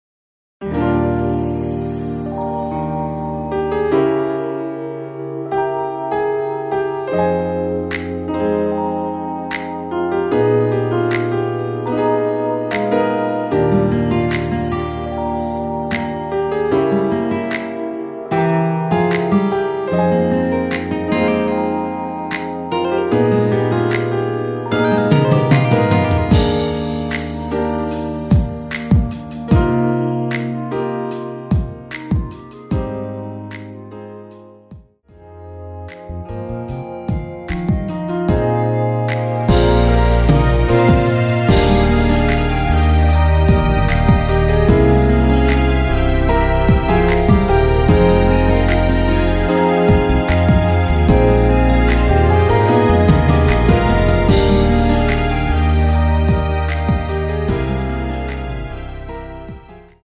원키에서(-1)내린 멜로디 포함된 MR입니다.
Eb
앞부분30초, 뒷부분30초씩 편집해서 올려 드리고 있습니다.
중간에 음이 끈어지고 다시 나오는 이유는